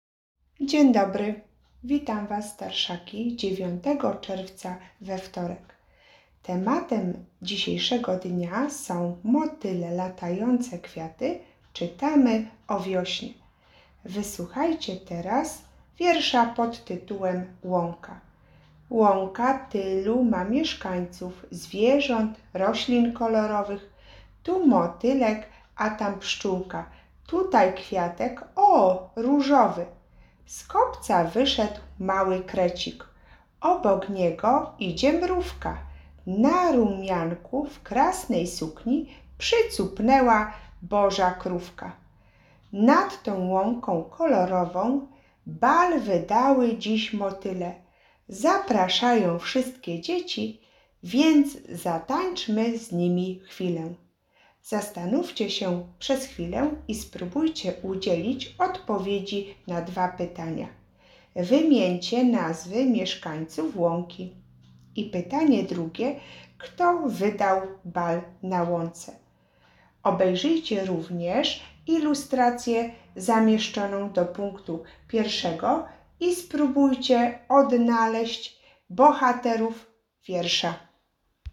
1. „ Łąka”- wysłuchanie wiersza I. R Salach.